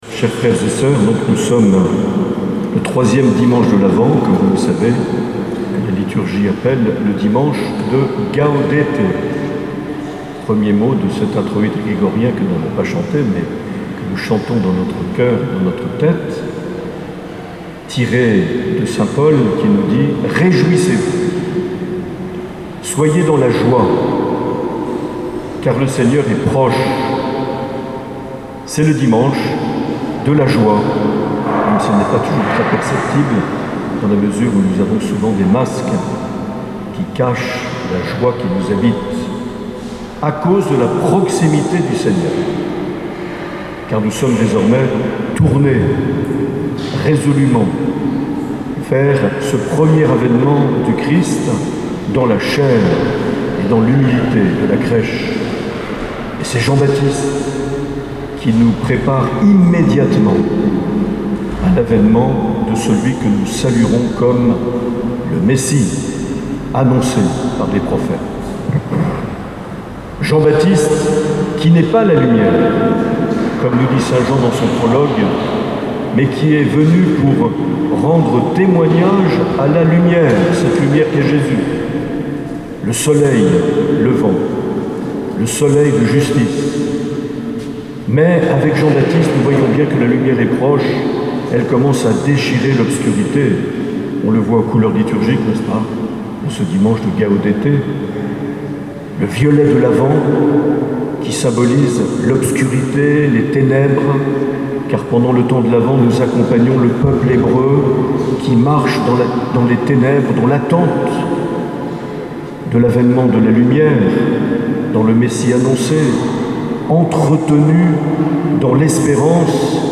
13 décembre 2020 - Cathédrale de Bayonne - Bénédiction de l'orgue de choeur
Accueil \ Emissions \ Vie de l’Eglise \ Evêque \ Les Homélies \ 13 décembre 2020 - Cathédrale de Bayonne - Bénédiction de l’orgue de choeur et (...)
Une émission présentée par Monseigneur Marc Aillet